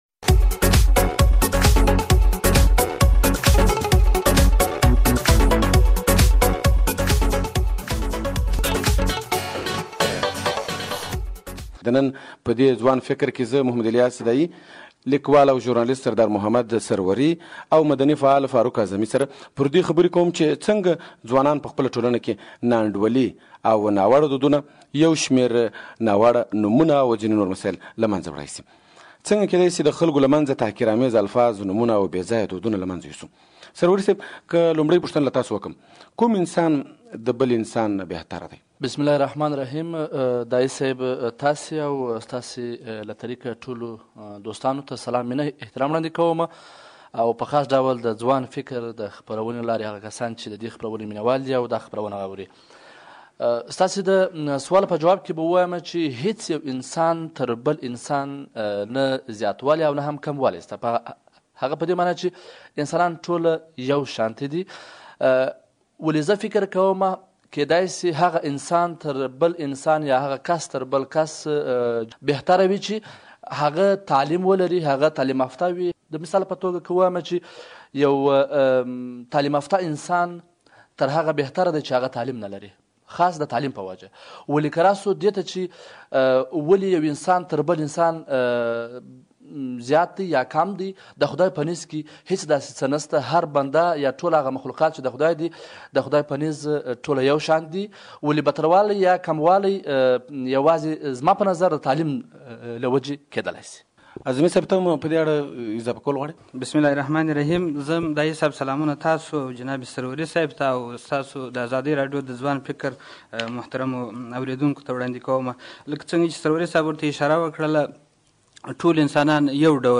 د ځوان فکر د دې اوونۍ په پروګرام کې د هلمند له ځينو روڼ اندو ځوانانو سره خبرې شوې دي. له دوى سره دا مسئله څېړل شوې چې په ټولنه کې ځينې ناوړه دودونه او نومونه څنګه له وړل کېدلاى شي؟